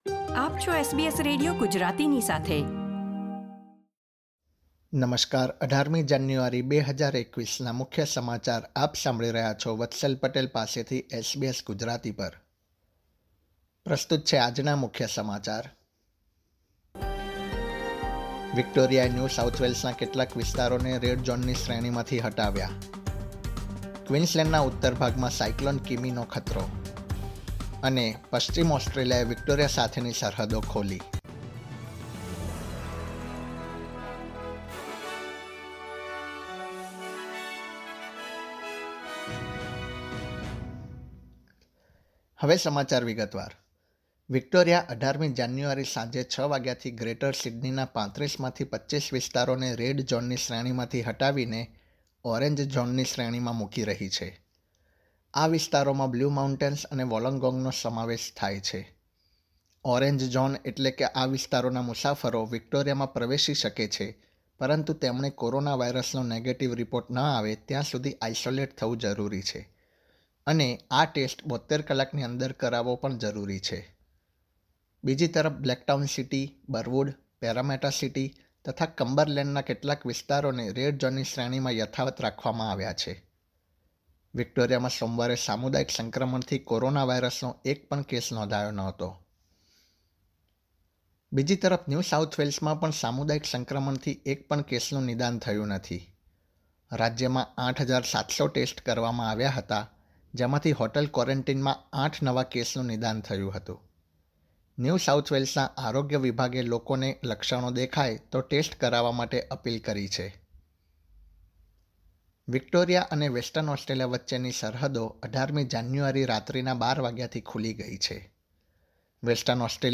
SBS Gujarati News Bulletin 18 January 2021